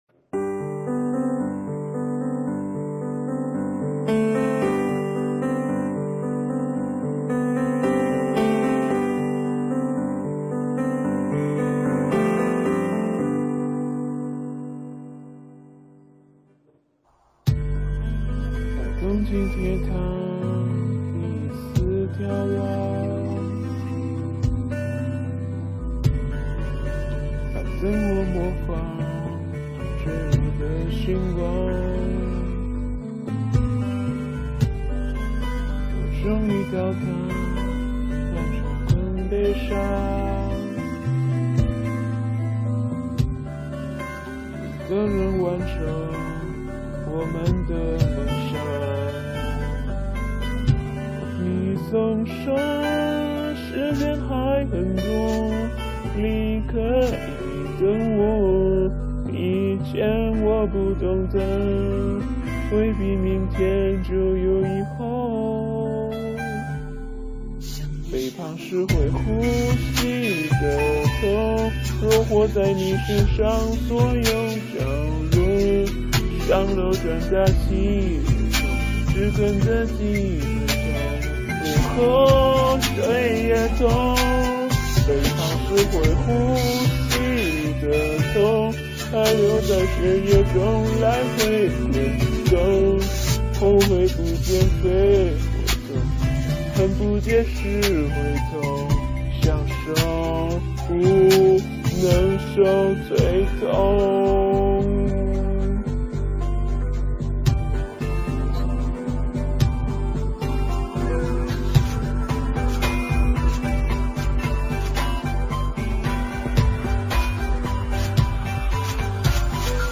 不过也唱出了很多人的心声哦哦哦 第一个用了狗狗变声器，结果就变成 ...